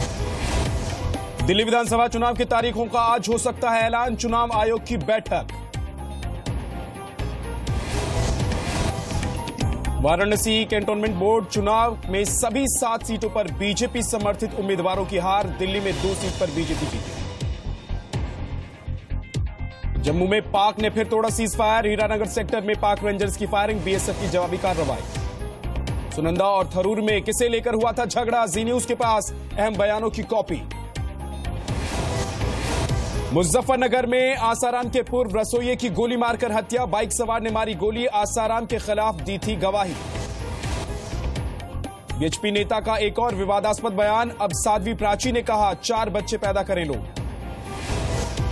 Listen to top headlines of the day